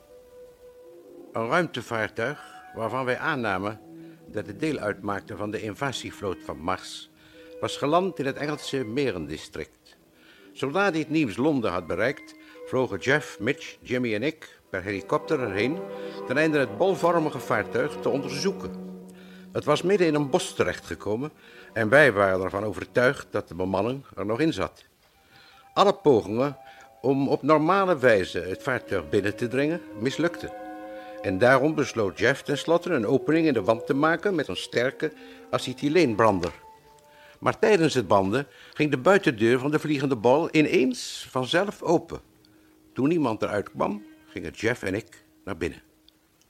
Net als in de Nederlandse versie start iedere Britse aflevering met het voorlezen van Doc. uit zijn dagboek. Hierdoor worden we als luisteraar even teruggebracht naar het einde van de vorige aflevering en vinden we zo de aansluiting naar de nieuwe aflevering.